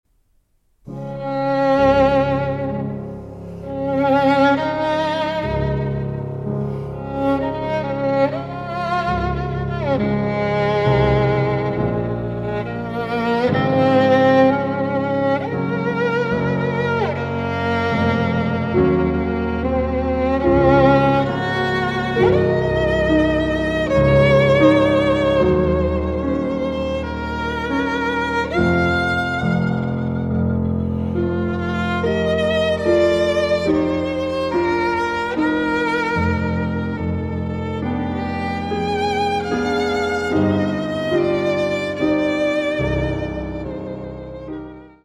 for violin and piano